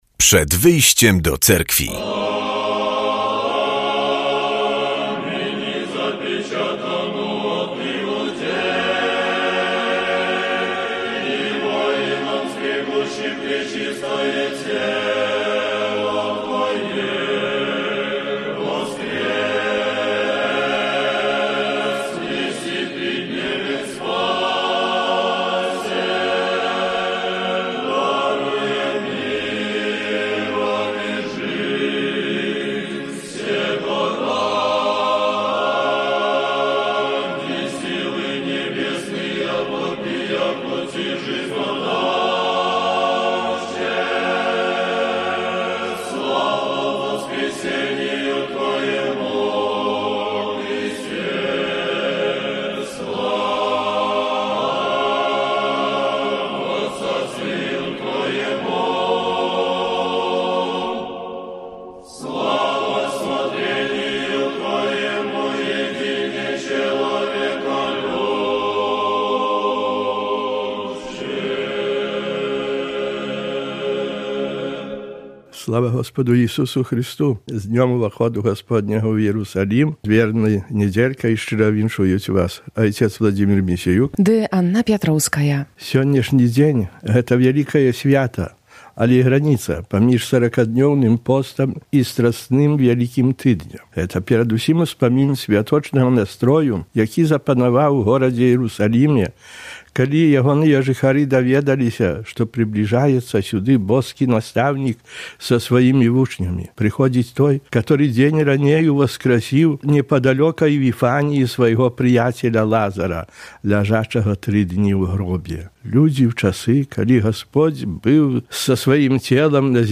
W audycji usłyszymy kazanie na temat niedzielnej Ewangelii i informacje z życia Cerkwi prawosławnej. Porozmawiamy także o Akcji Lato - aktywnym wypoczynku z Bractwem Młodzieży Prawosławnej w Polsce.